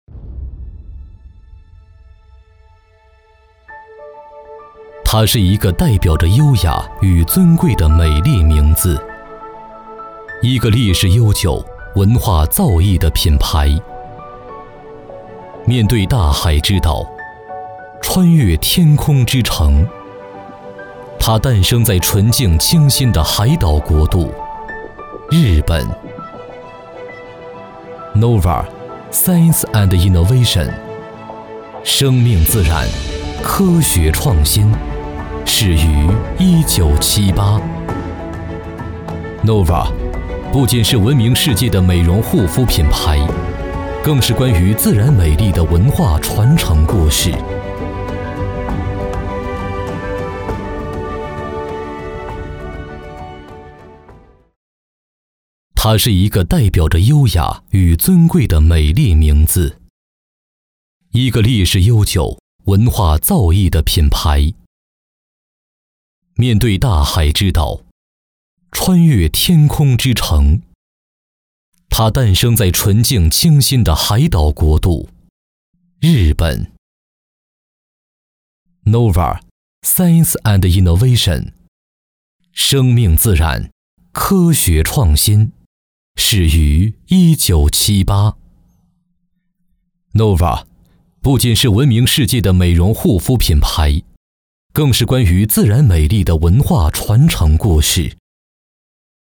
宣传片-男52-大气沉稳 noevir品牌日本溯源.mp3